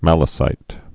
(mălə-sīt)